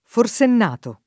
forsennato [ for S enn # to ]